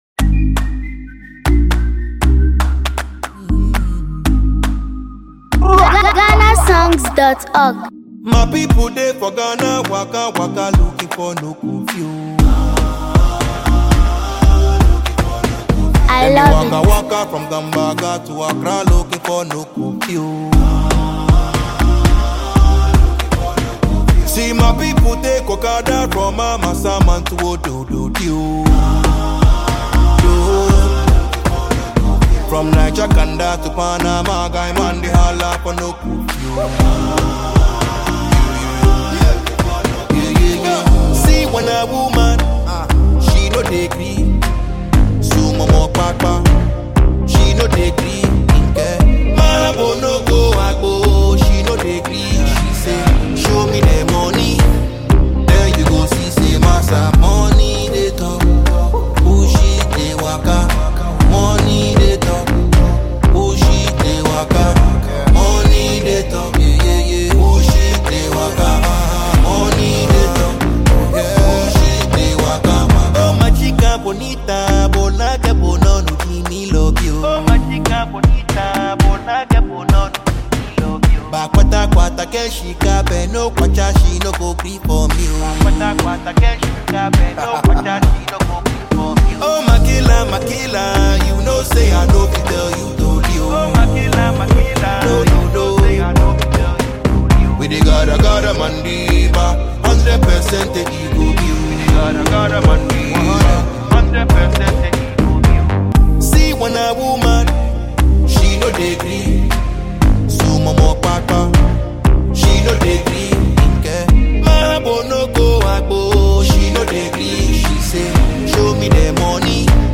Award-winning Ghanaian rapper and producer
The production is raw, rhythmic, and street-inspired